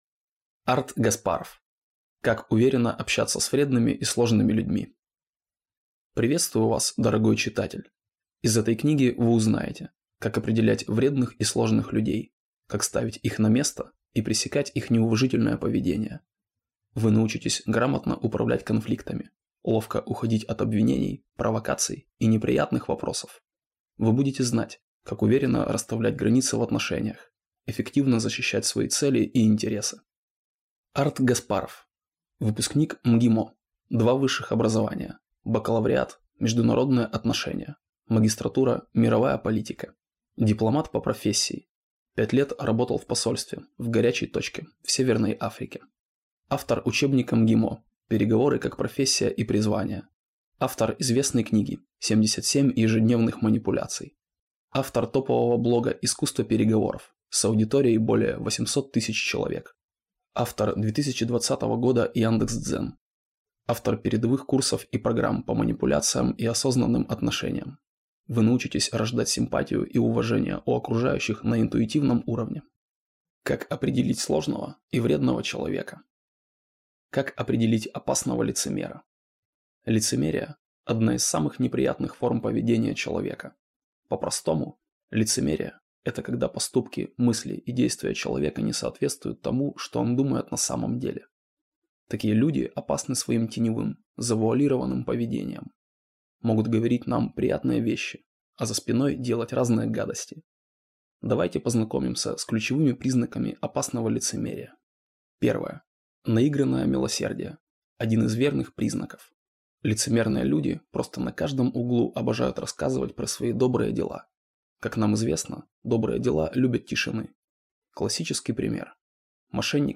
Аудиокнига Как уверенно общаться с вредными и сложными людьми | Библиотека аудиокниг